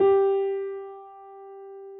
piano_055.wav